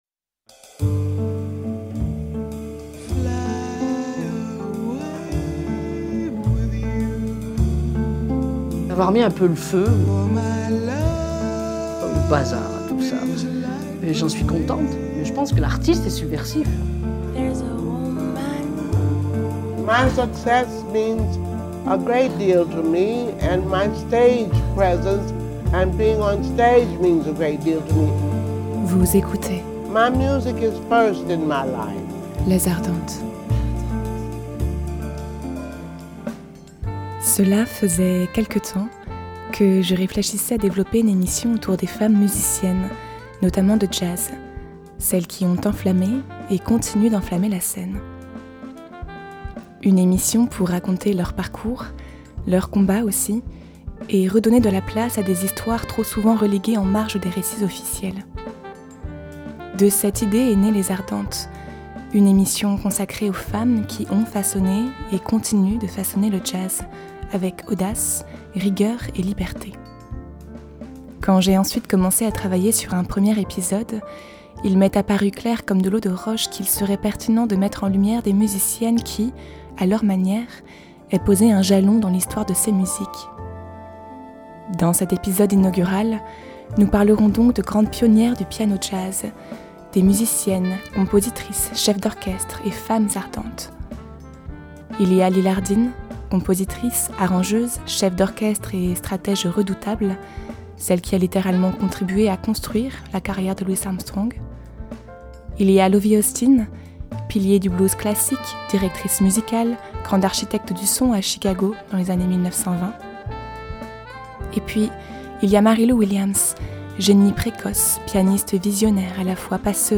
Une plongée dans l’histoire du genre, à l’écoute de trois instrumentistes féminines qui en ont posé des fondations.
Classique & jazz